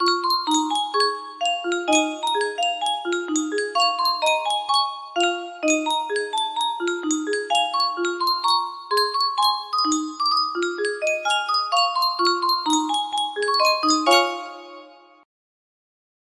Yunsheng Music Box - Sing a Song of Sixpence Y518 music box melody
Full range 60